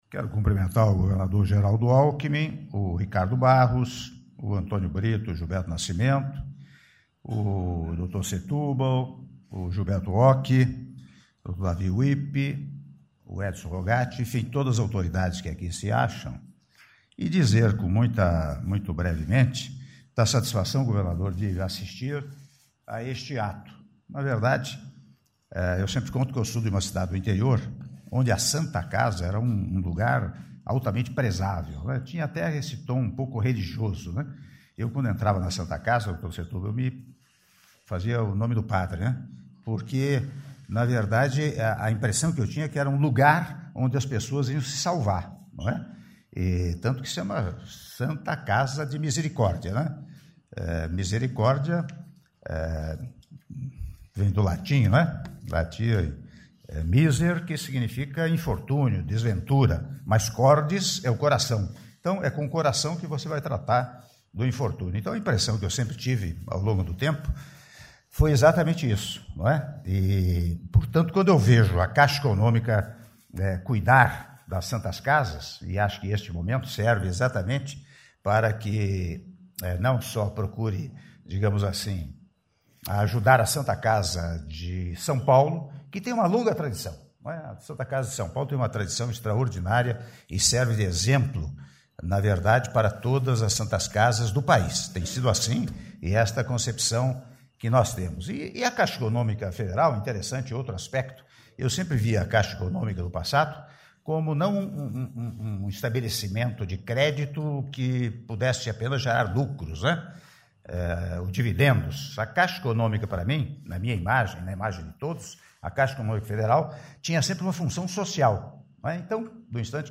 Áudio do discurso do presidente da República, Michel Temer, na cerimônia de Assinatura de Protocolo de Intenções de Estruturação Financeira da Santa Casa de São Paulo - Brasília/DF (03min17s) — Biblioteca